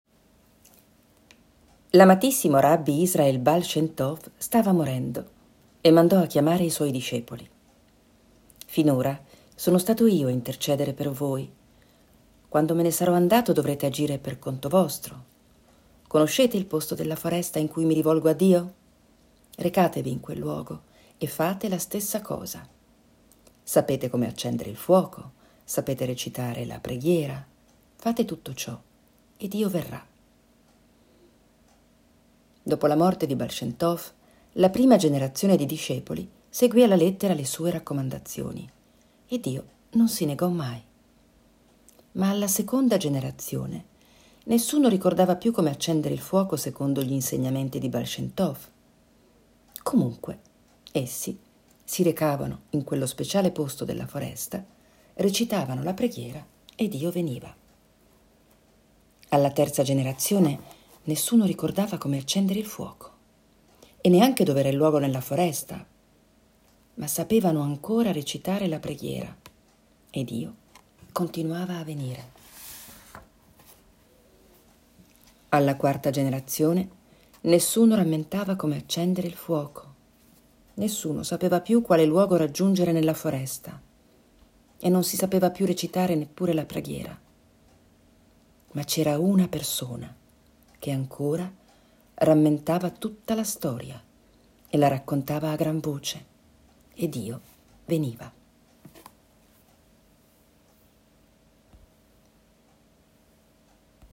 • Per chiudere questa classifica leggo una delle storie del libro che ho appena citato, un piccolo regalo con tutti i miei auguri per le prossime Feste!